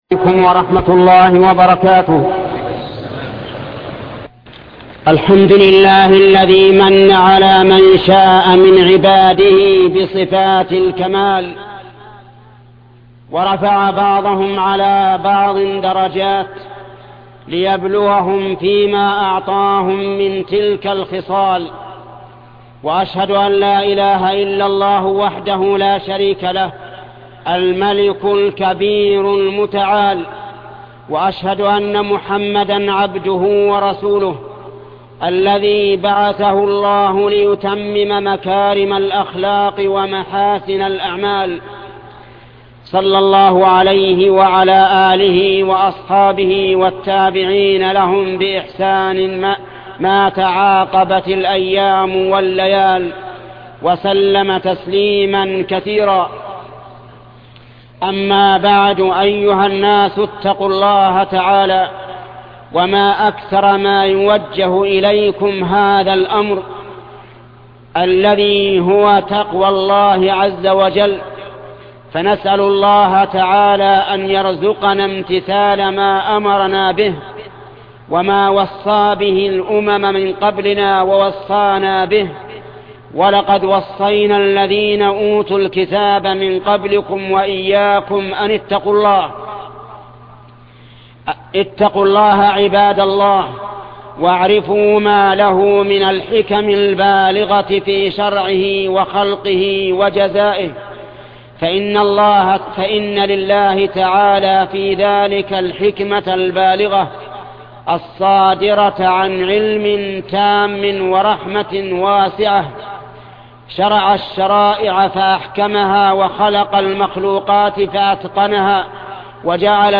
خطبة من صفات النبي الخلقية والخلقية الشيخ محمد بن صالح العثيمين